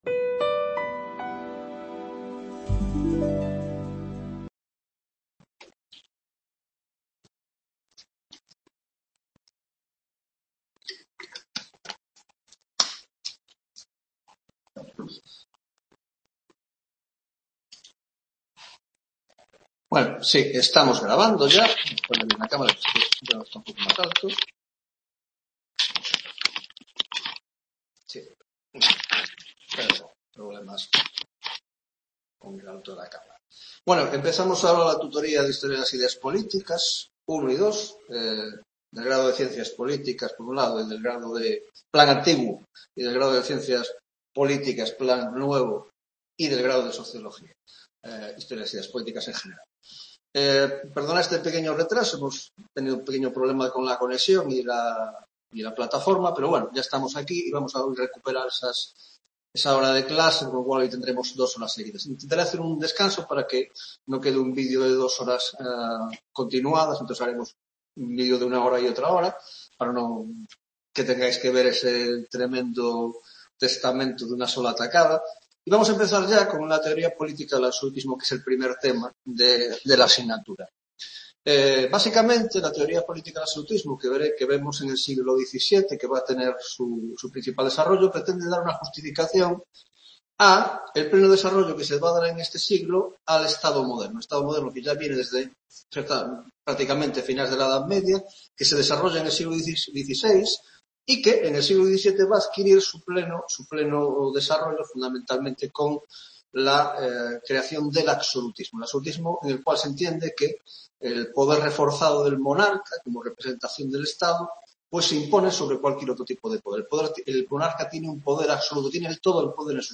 1ª Tutoría de Historia de las Ideas Políticas II (Grado de Ciencias Políticas )